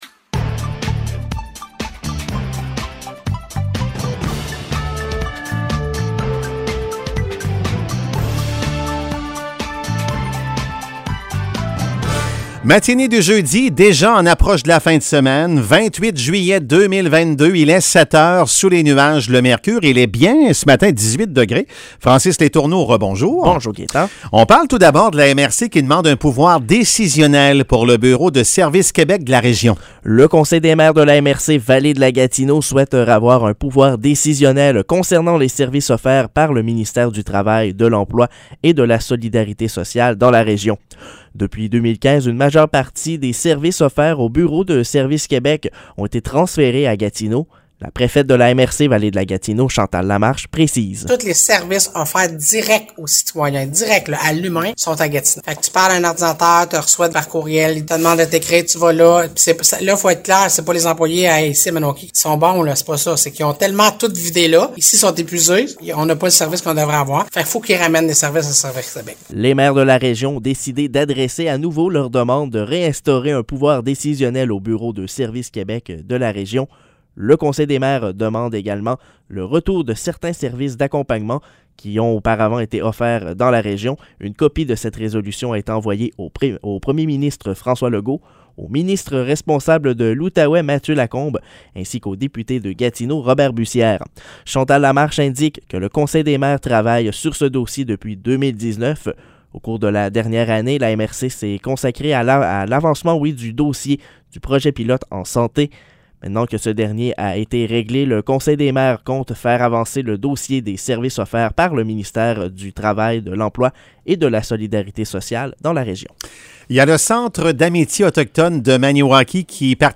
Nouvelles locales - 28 juillet 2022 - 7 h